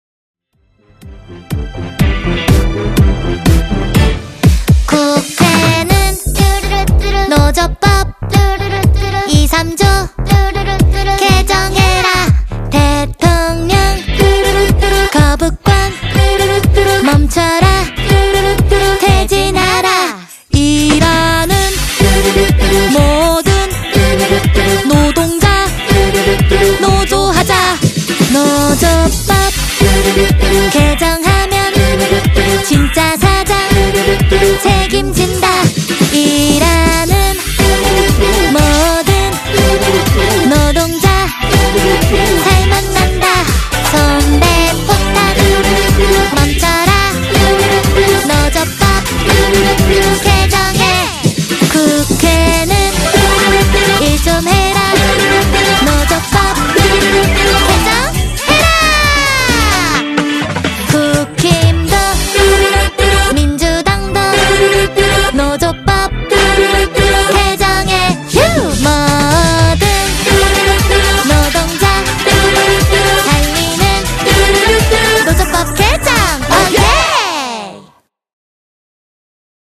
노조법 2,3조 개정 개사곡 음원 다운로드 :